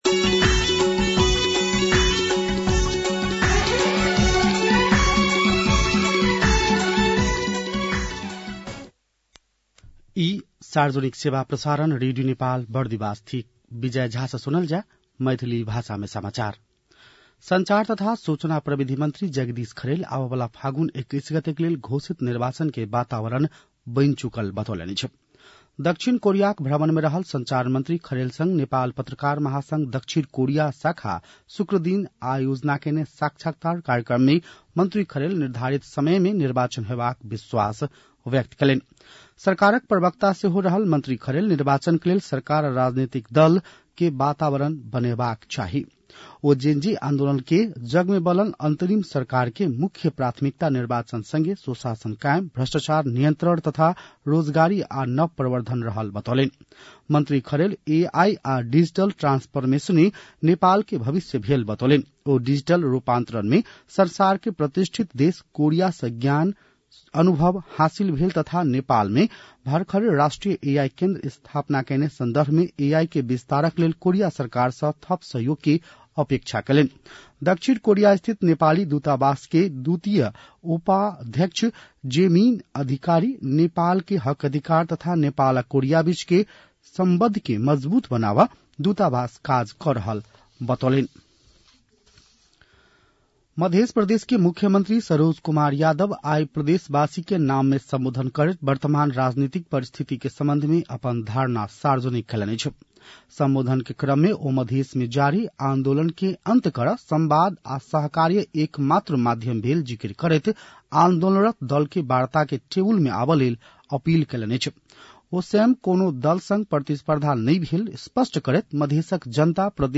मैथिली भाषामा समाचार : २९ कार्तिक , २०८२
6.-pm-maithali-news-1-1.mp3